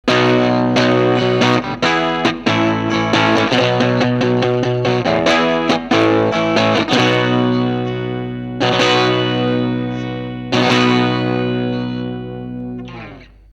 ハイインプット＆フルヴォリュームです。
真空管を暖めてからテストに突入しています。